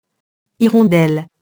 hirondelle [irɔ̃dɛl]